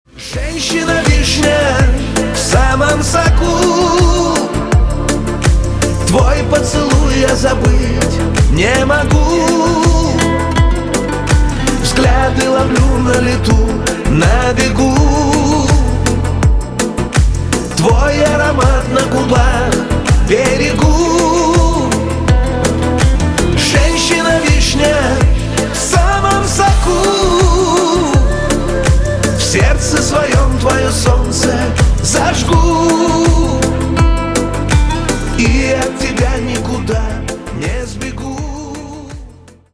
Романтические рингтоны / Шансон рингтоны